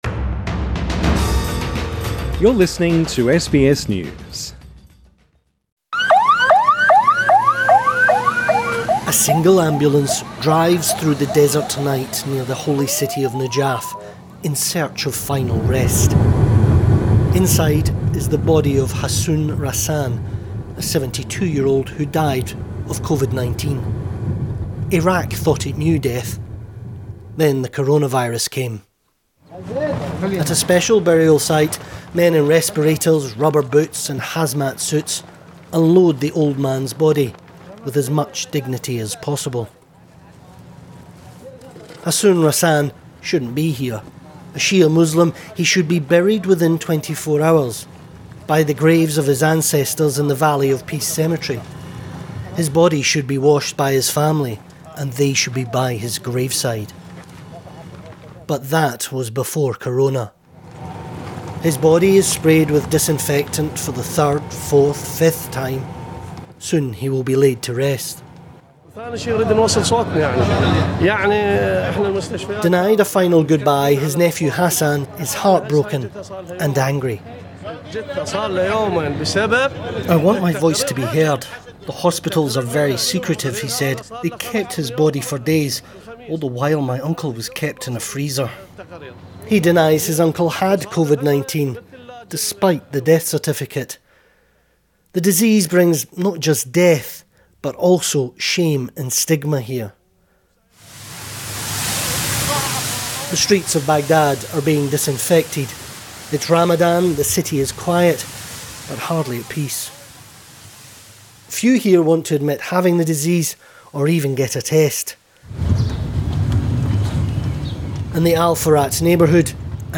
This story was originally produced for BBC World Service Radio